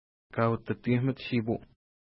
Pronunciation: ka:utəti:hkumət-ʃi:pu:
Pronunciation